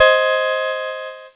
Cheese Chord 26-G#4.wav